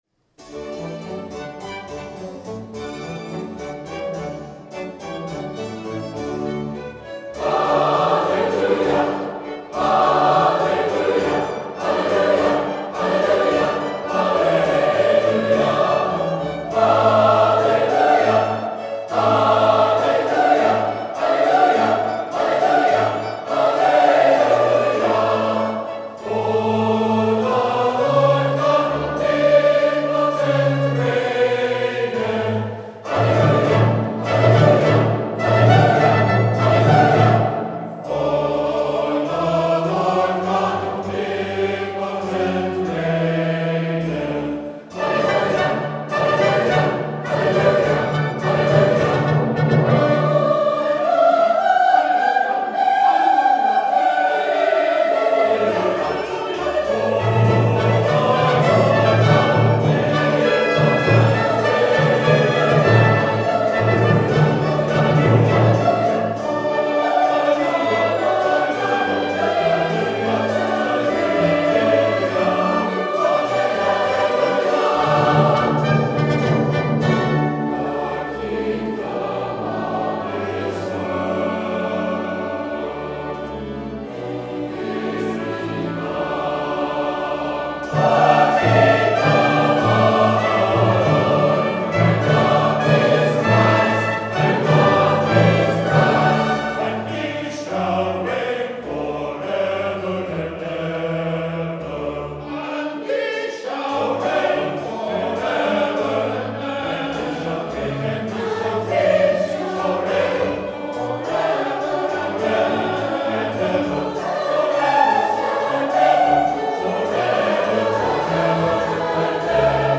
42-Hallelujah-Chorus.mp3